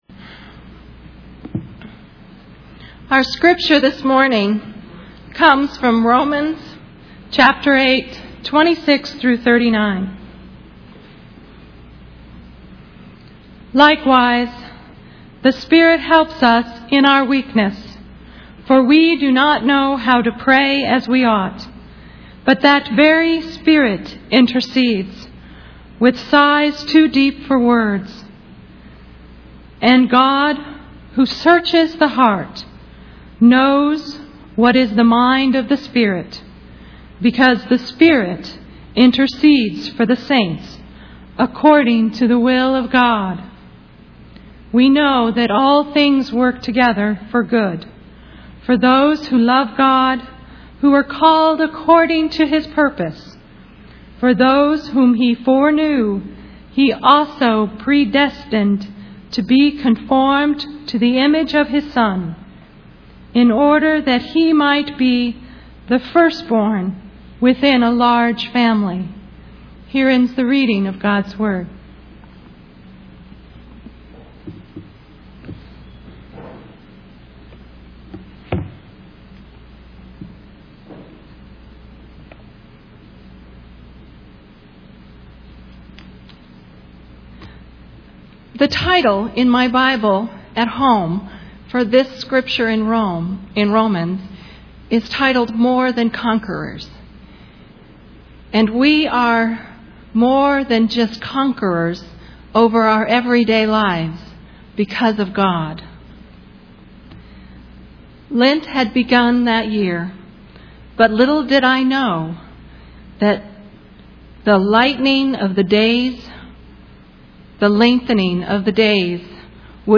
Sunday's Sermon "More Than Conquerors"